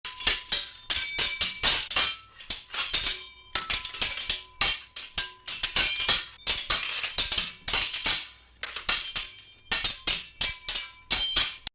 Metal Clank
yt_dnZ5c4zDSpk_metal_clank.mp3